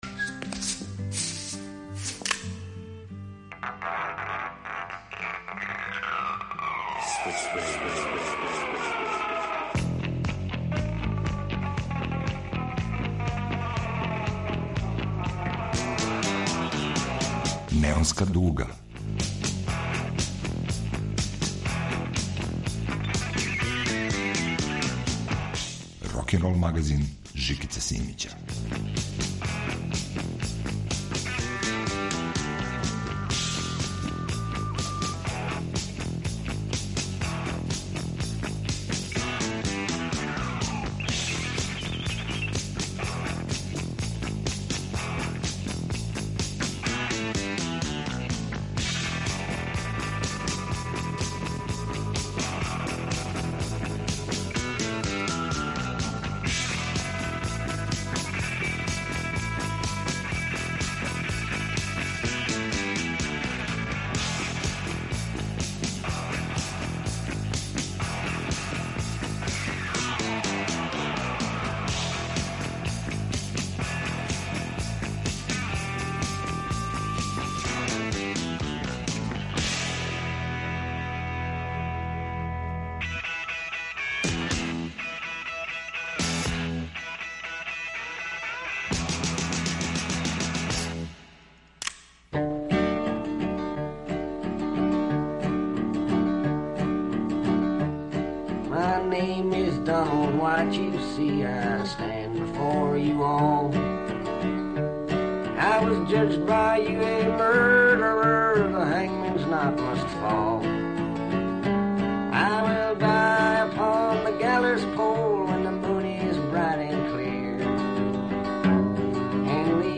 Рокенрол као музички скор за живот на дивљој страни.